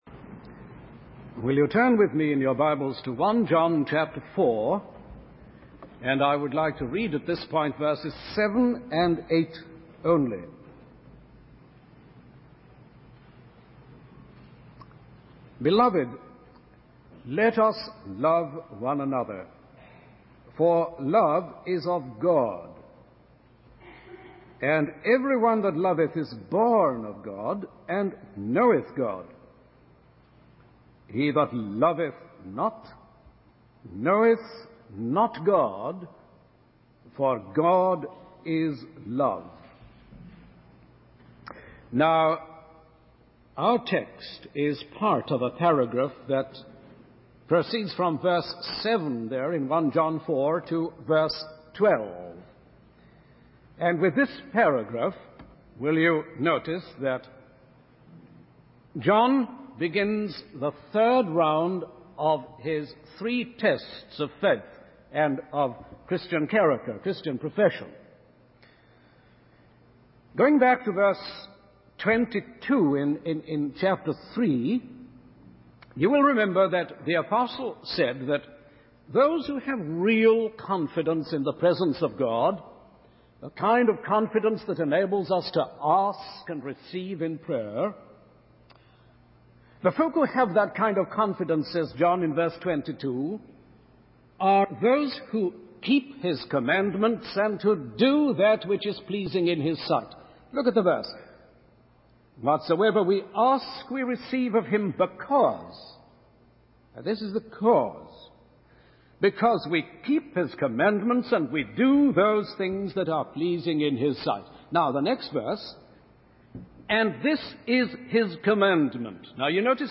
In this sermon, the speaker confesses that due to a pastoral situation, he will only be addressing verses seven and eight of 1 John 4.